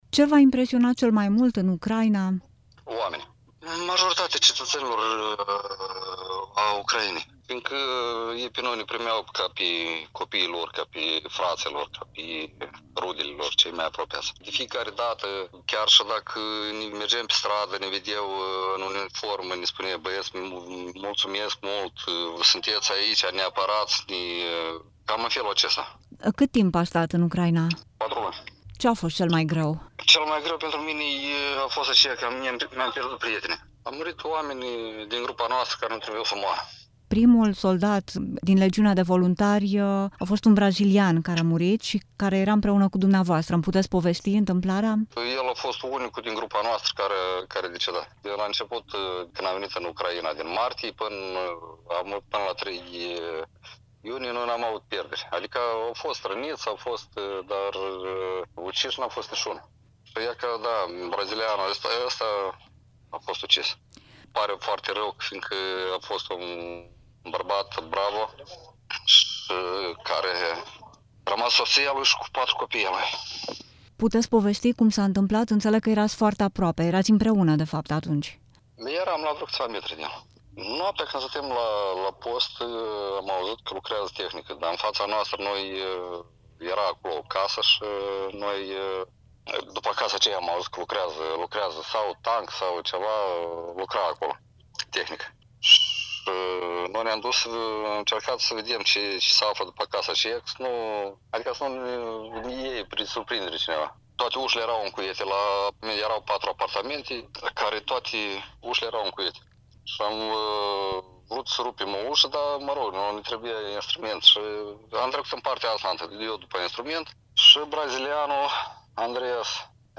Soldat voluntar în Ucraina, despre război: „Rușii nu au nimic sfânt în ei. Violează copii, femei, bărbați. E un barbarism de Evul Mediu” | INTERVIU